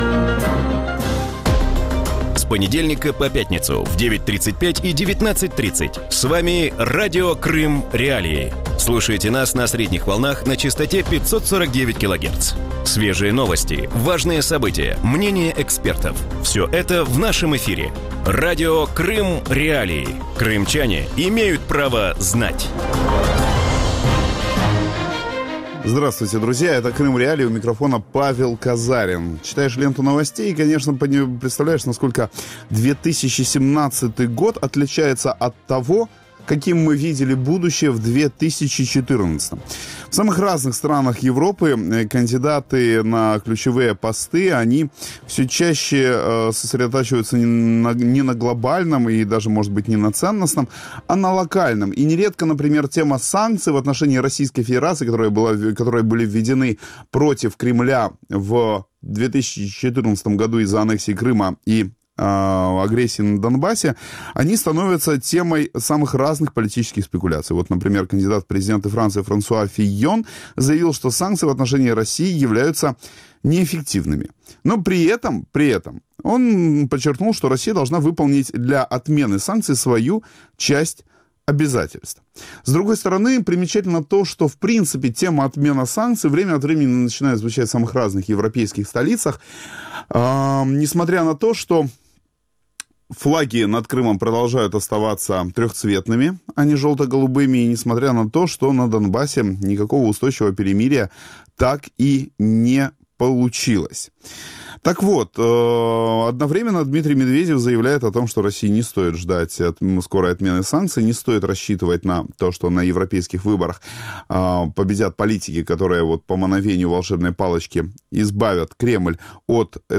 У вечірньому ефірі Радіо Крим.Реалії говорять про скепсис російських чиновників щодо скасування антиросійських санкцій. Чи звикли Росія і Крим до санкційного тиску, як обмеження впливають на російську економіку через три роки після їх введення і якої політики щодо Росії можна очікувати від нових світових лідерів?